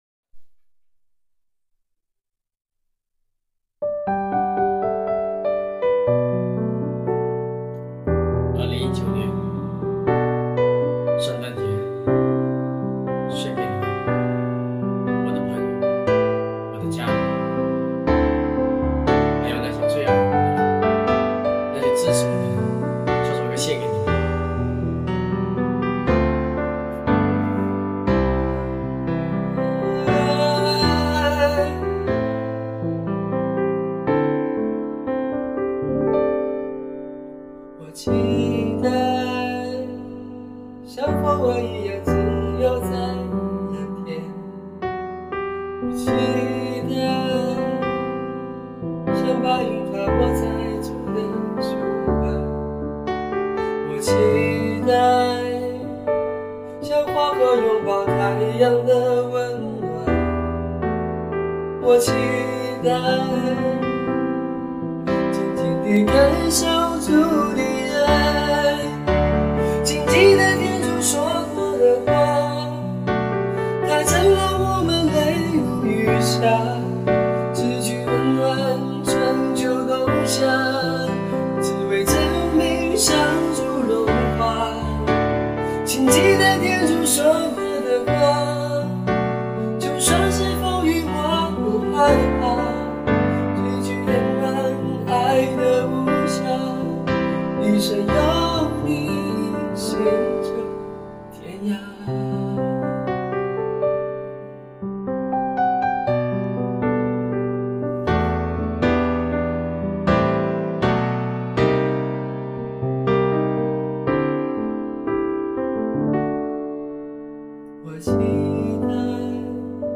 【原创圣歌】|《期待》